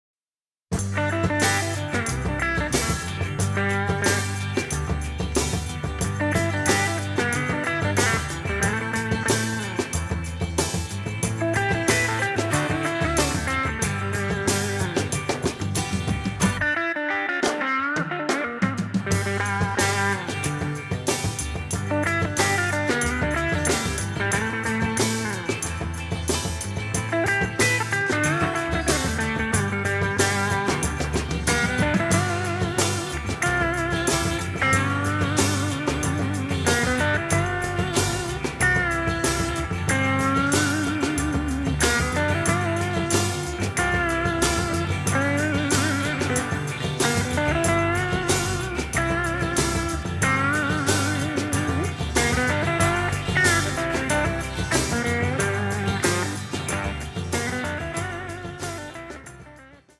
mixed in mono